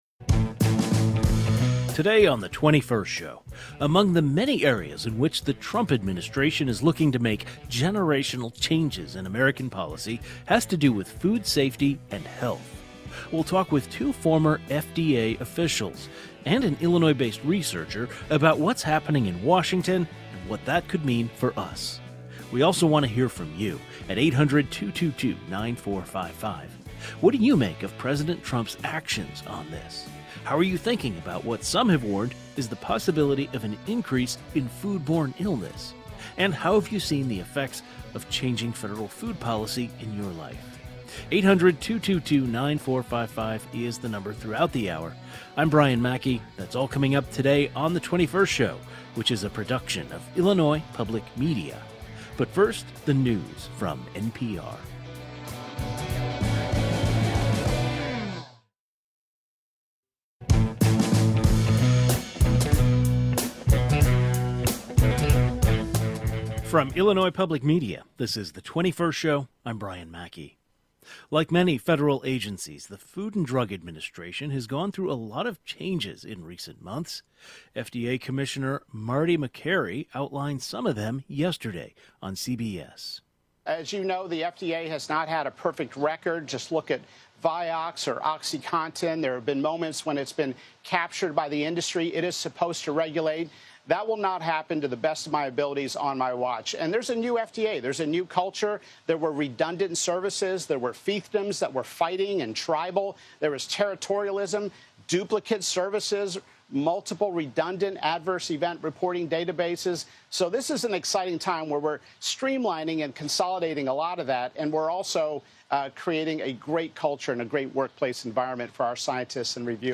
A panel of experts on public health and food safety join the conversation.